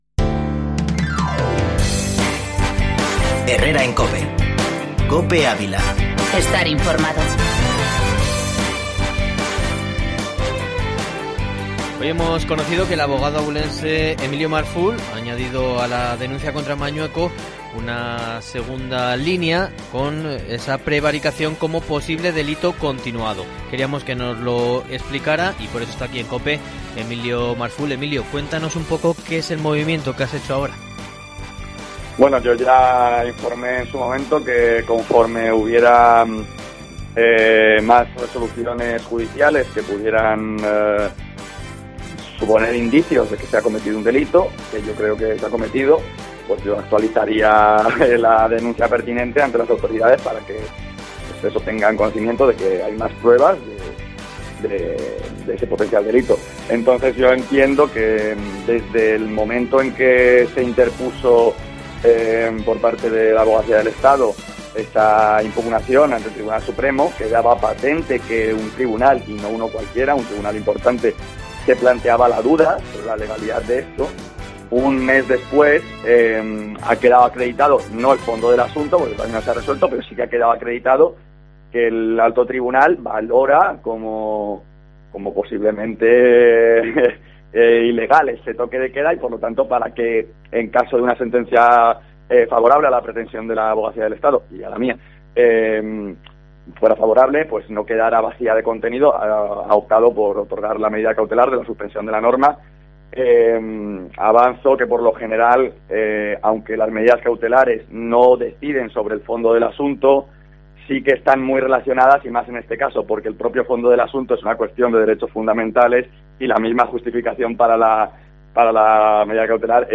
Herrera en COPE en Ávila Entrevista